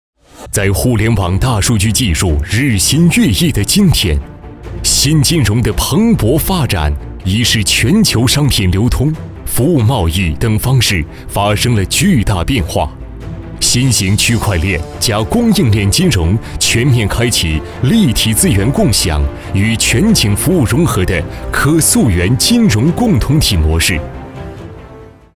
男国443_专题_企业_鼎云AI服务_大气.mp3